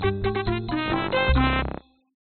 游戏碰撞音效
描述：游戏碰撞音效件，用Zoom H4n记录，切成Audacity
标签： 游戏 碰撞 声音 骨头 动画片
声道立体声